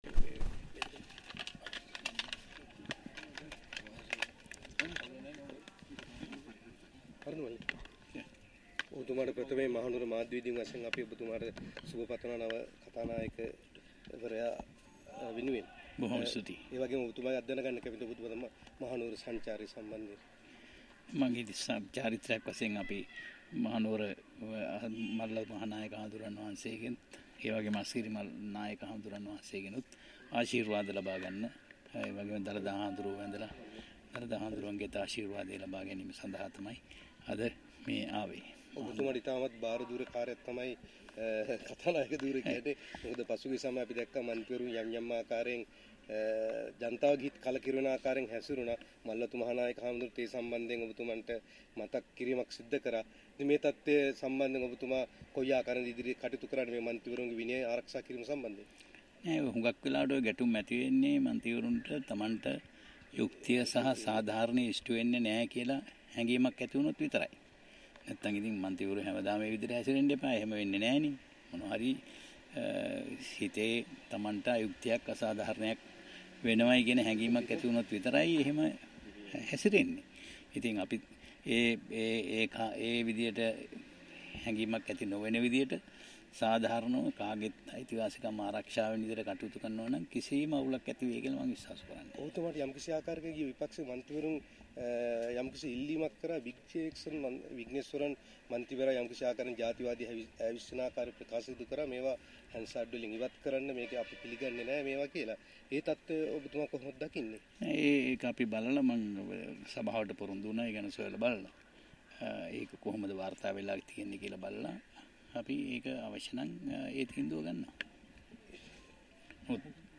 කථානායකවරයා මහනුවර මල්වතු, අස්ගිරි මහ නාහිමිවරු බැහැදැක ආශිර්වාද ලබා ගැනීමට පැමිණි අවස්ථාවේ මාධ්‍යවේදීන් මෙම ප්‍රශ්නය ගැන විමසු ගැන අවස්ථාවේ කථානාකවරයා මේ බව ප්‍රකාශ කර සිටියේය.